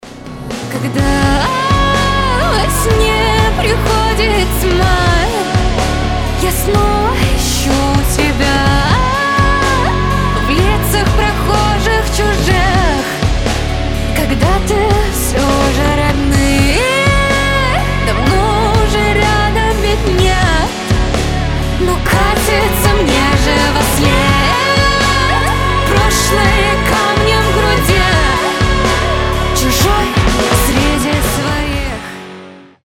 • Качество: 320, Stereo
грустные
атмосферные
красивый вокал